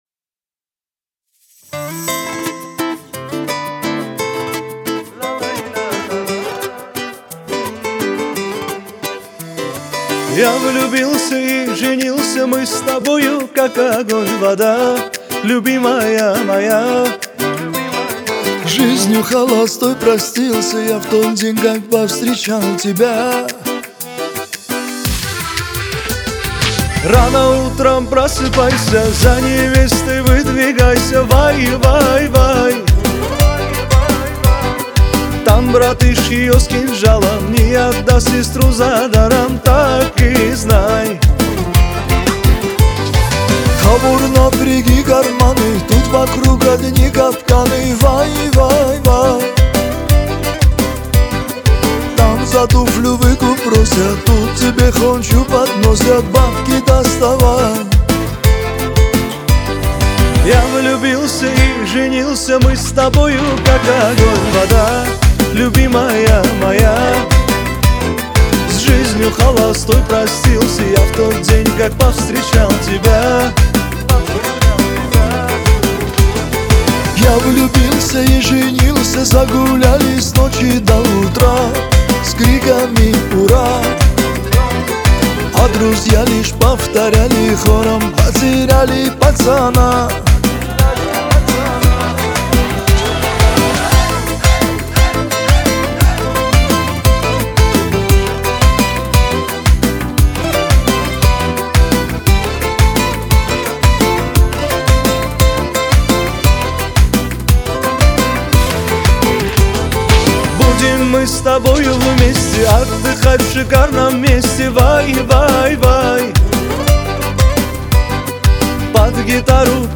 Качество: 320 kbps, stereo
Кавказская музыка